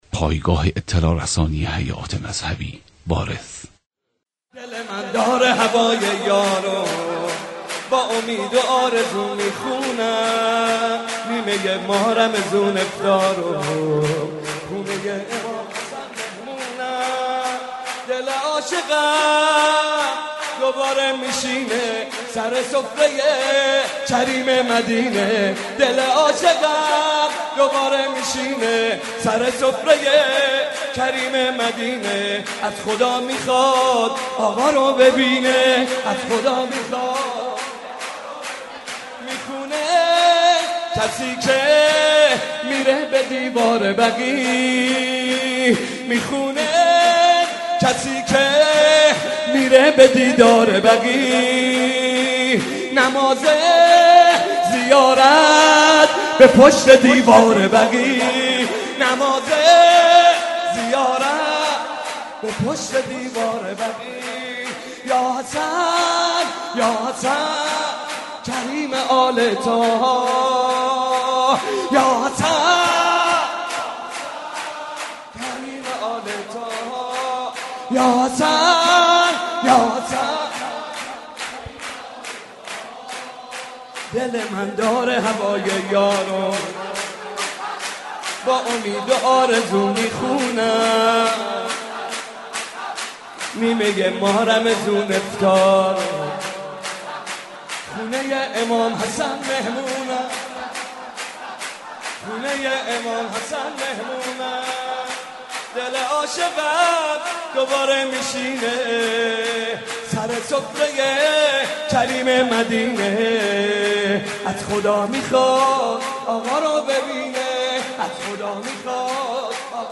مولودی حاج محمد رضا طاهری به مناسبت میلاد با سعادت امام حسن مجتبی (ع)
هیئت مکتب الزهرا س